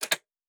Sci-Fi Sounds / Mechanical / Device Toggle 10.wav
Device Toggle 10.wav